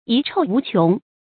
遺臭無窮 注音： ㄧˊ ㄔㄡˋ ㄨˊ ㄑㄩㄥˊ 讀音讀法： 意思解釋： 謂壞名聲永遠流傳下去，而無窮盡之日。